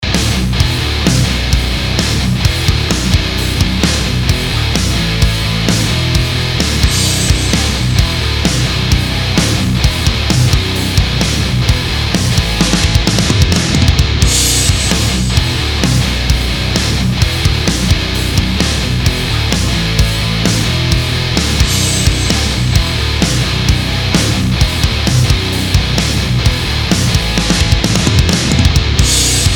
Fat Metal Direct VST ����� �����.